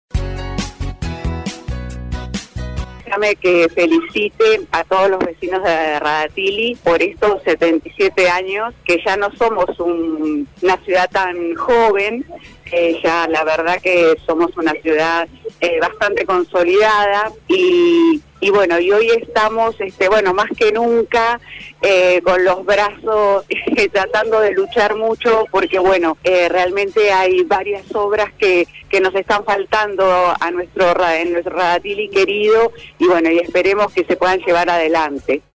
En el marco del acto central por un nuevo aniversario de la villa balnearia, la concejal de Arriba Chubut, Mabel Morejón, dialogó con LA MAÑANA DE HOY y dejó su saludo: